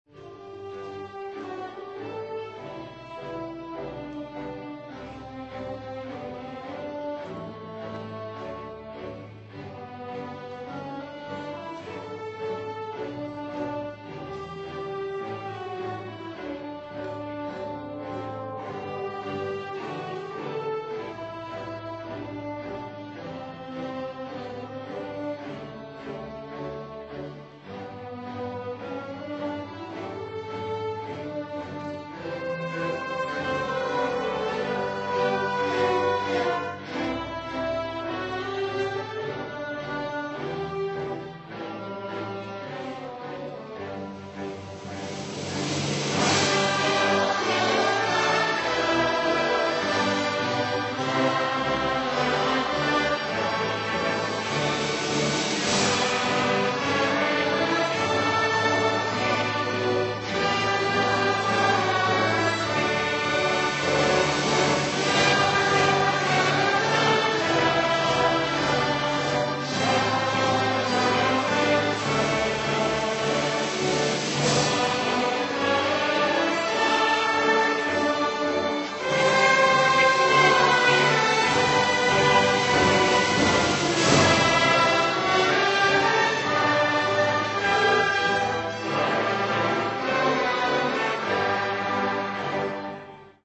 Concert on Sunday 25th June 2000
Peel Hall, Salford University